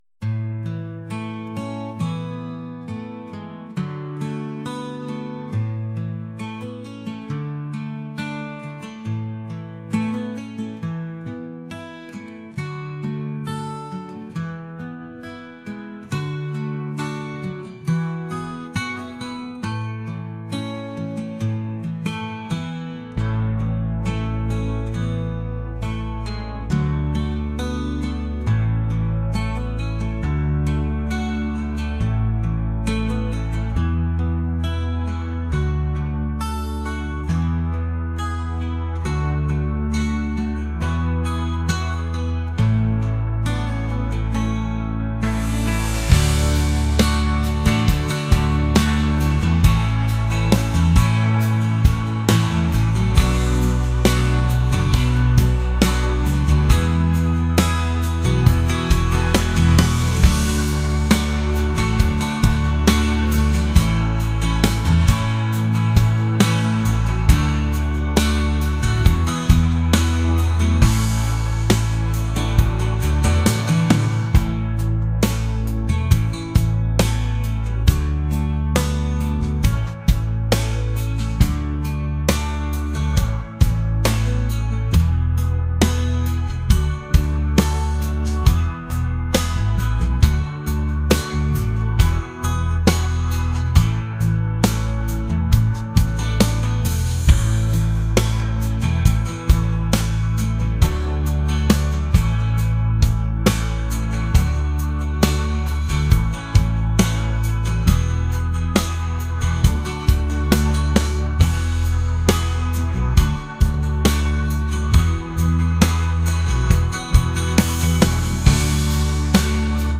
acoustic | pop | ambient